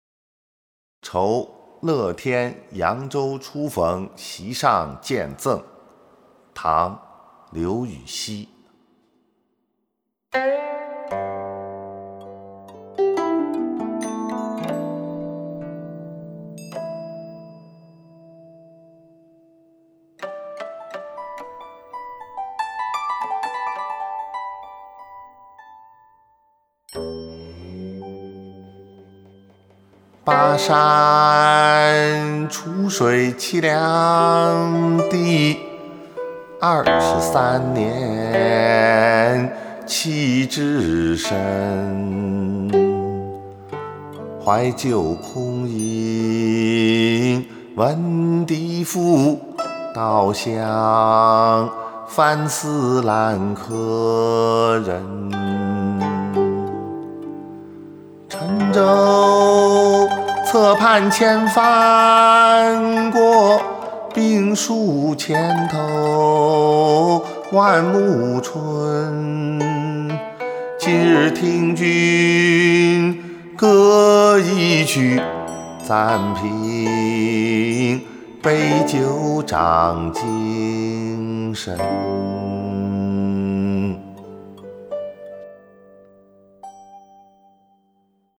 ［唐］刘禹锡 《酬乐天扬州初逢席上见赠》（吟咏）